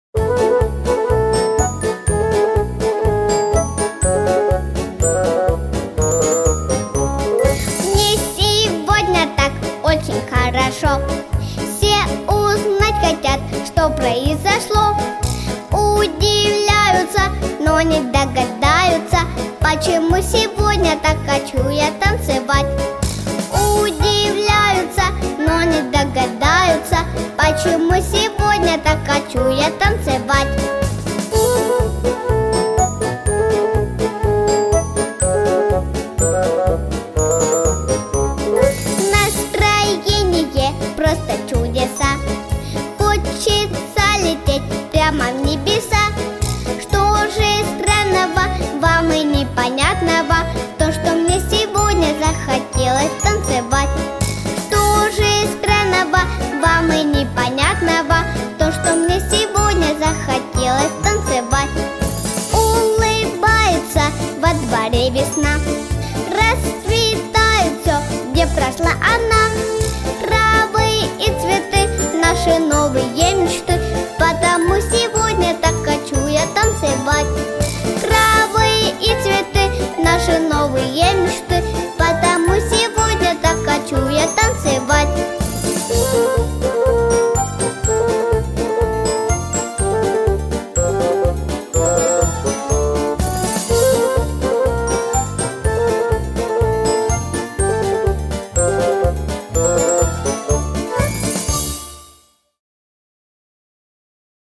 • Категория: Детские песни
Слушать минус